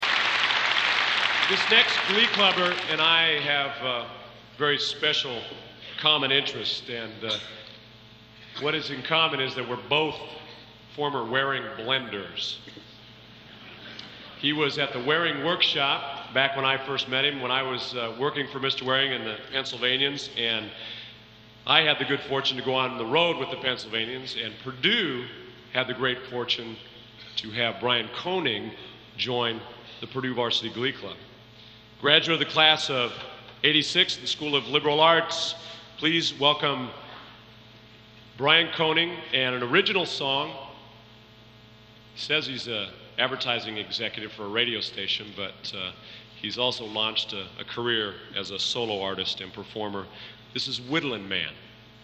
Collection: Centennial Celebration Concert 1993
Genre: | Type: Director intros, emceeing